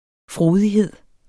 Udtale [ ˈfʁoːðiˌheðˀ ]